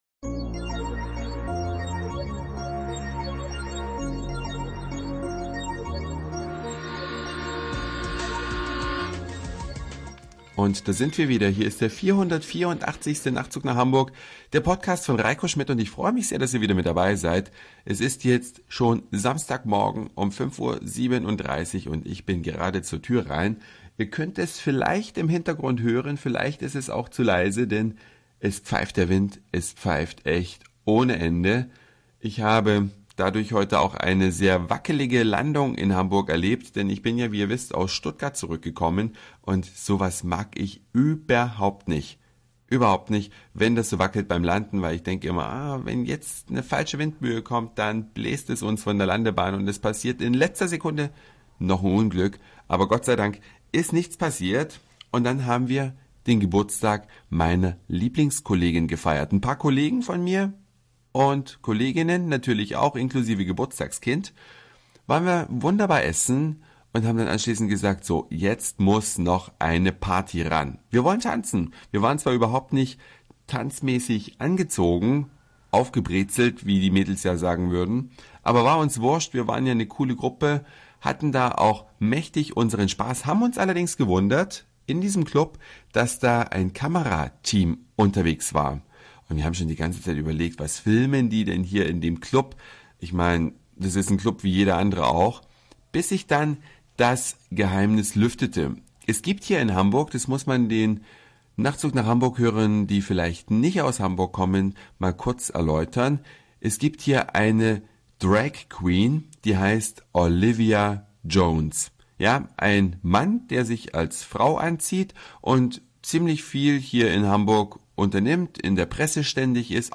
Hier pfeift es zum Fenster rein, so stürmisch ist es draußen.